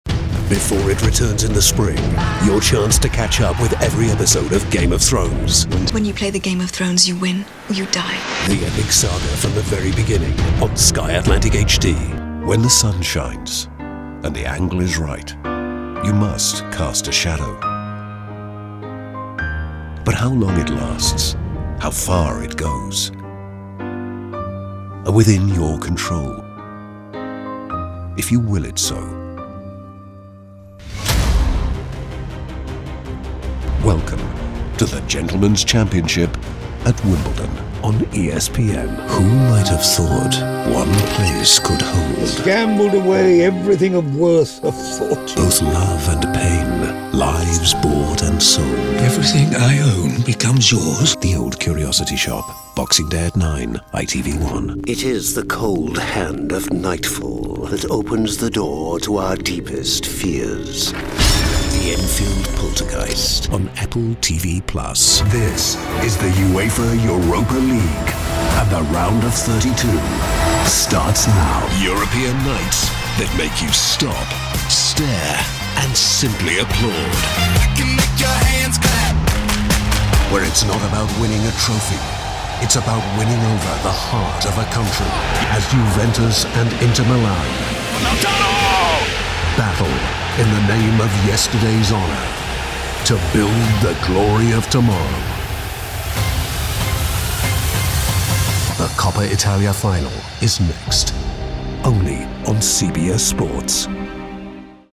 TV promo demo
Contemporary British English; English RP; London; Yorkshire; Liverpool; Scottish; West Country; Various European; Standard American
Middle Aged